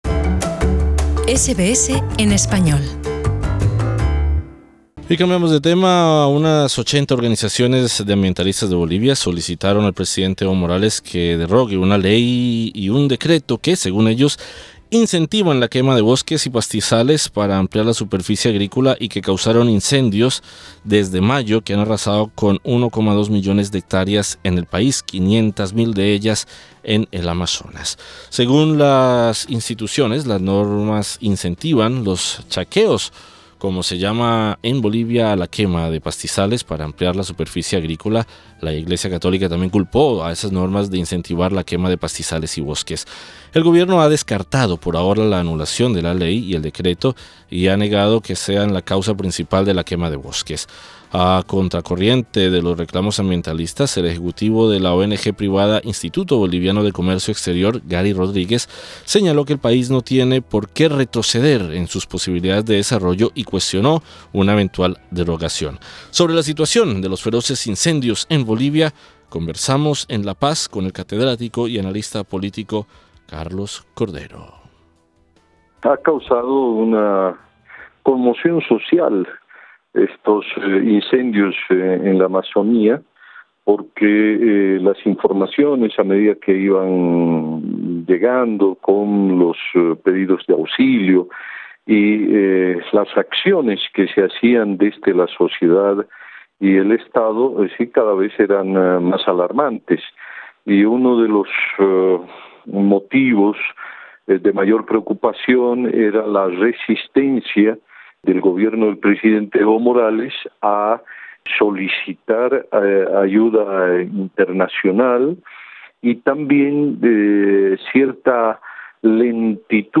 Sobre la situación de los feroces incendios en Bolivia, conversamos en La Paz con el catedrático y analista político,